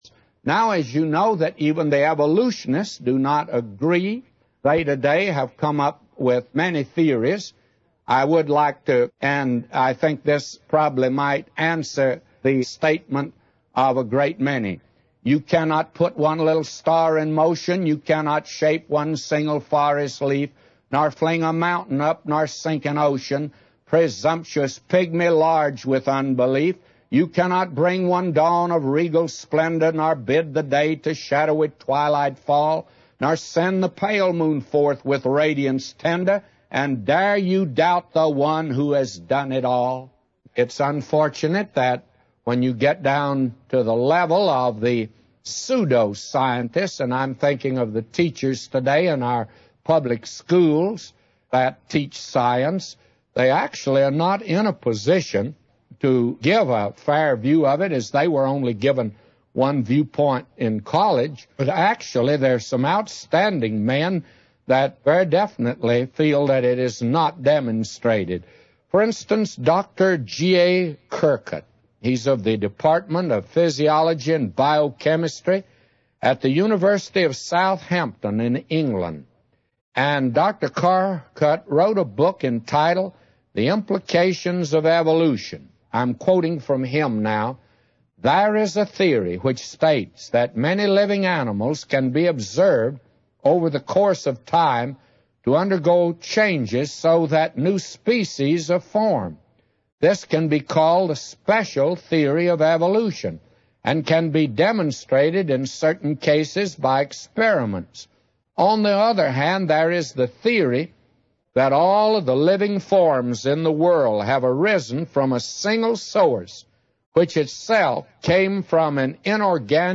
In this sermon, the preacher discusses the concept of evolution and its contrast with the biblical account of creation. He emphasizes that the true origin of the universe is unknown and that various theories exist, but ultimately, it is God who created everything out of nothing. The preacher criticizes some scientists' theories, such as the idea that humans originated from garbage or raw materials.